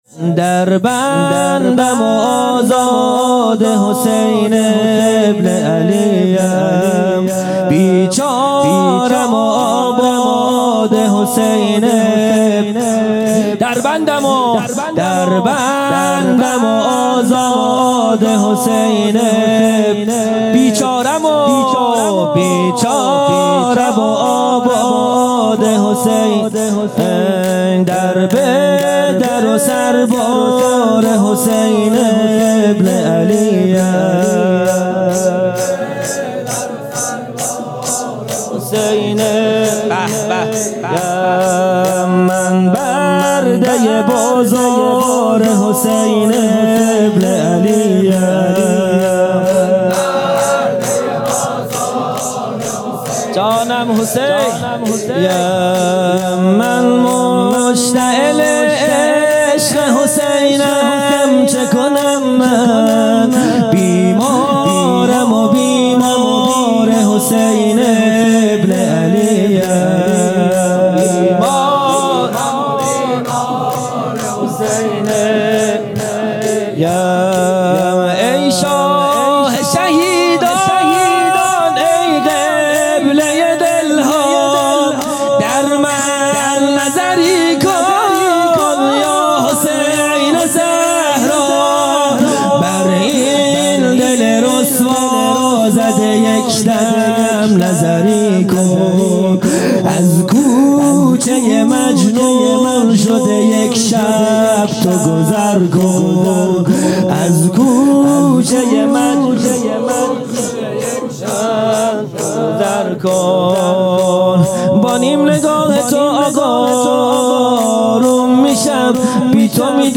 خیمه گاه - هیئت بچه های فاطمه (س) - شور | در بندم و آزاد حسین بن علیم
جلسۀ هفتگی